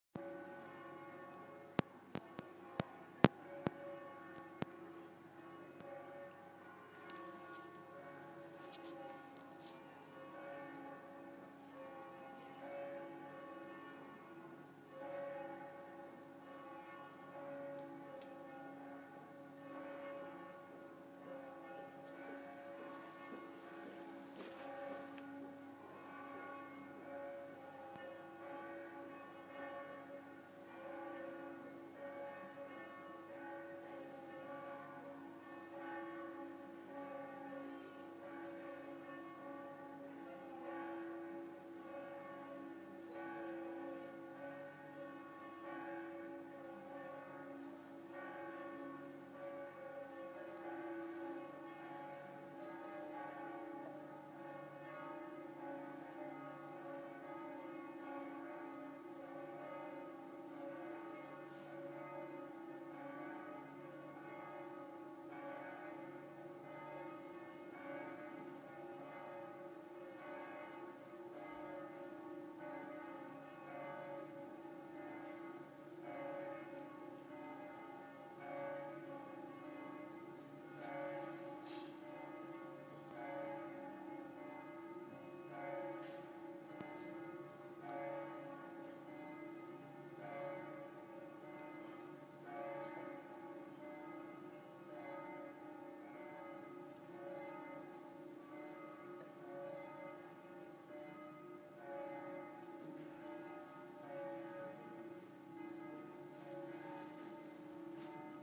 This is what Venice sounds like
Multiple church bells from where we're staying